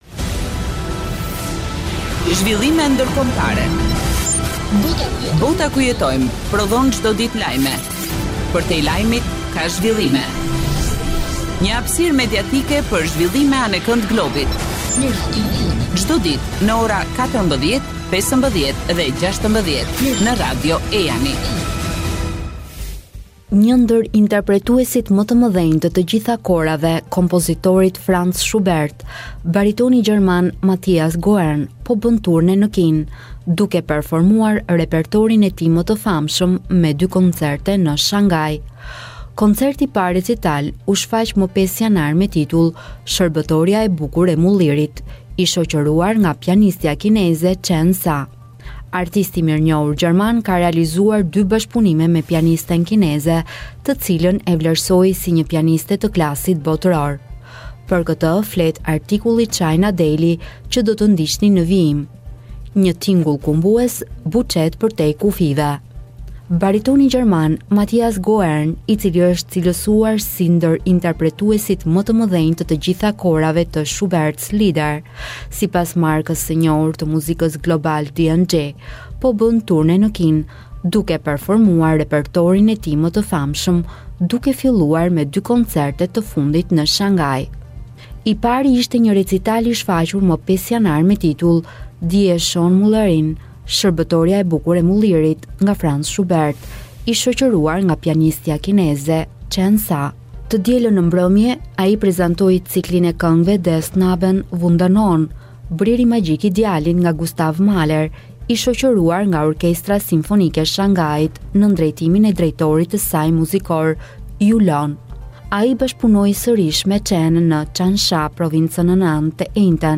Baritoni gjerman Matthias Goerne
recital